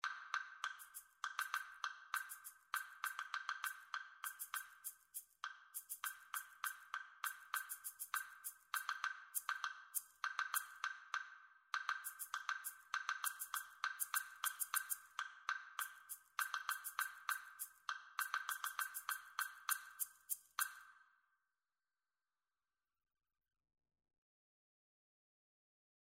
The first two pieces should create a conversation or discussion between the two instruments whilst the third and fourth piece should be written in more unusual time signatures. (5 and 7 beats to the bar)
3) Wood block & Shaker
project-2-duets-3-wood-block-shaker-audio.mp3